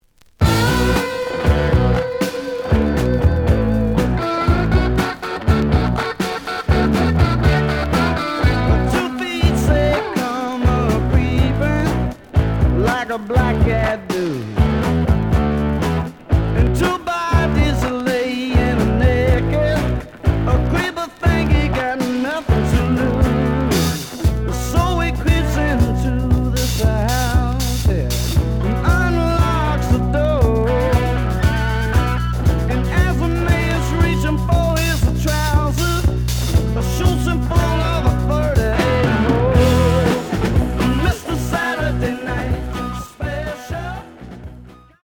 試聴は実際のレコードから録音しています。
●Genre: Rock / Pop
●Record Grading: VG~VG+ (両面のラベルに若干のダメージ。盤に若干の歪み。プレイOK。)